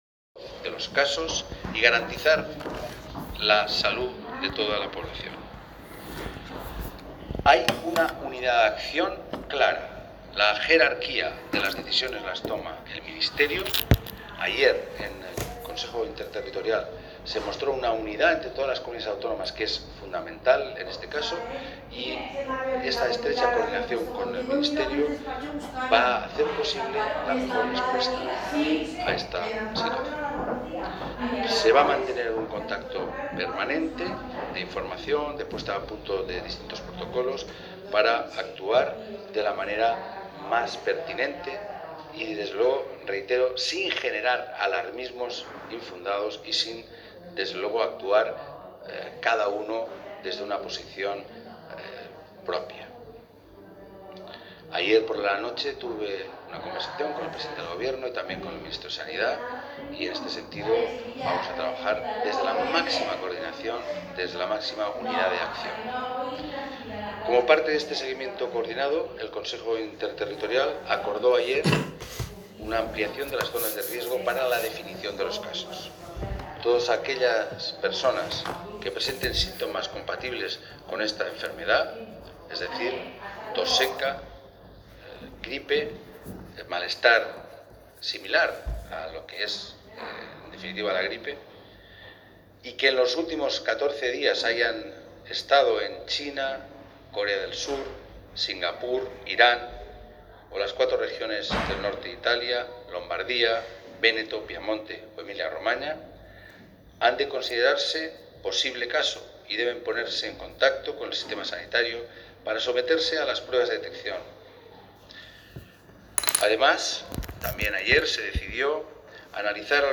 Diversos cortes de audio de las declaraciones de Ximo Puig en la mañana del día de hoy.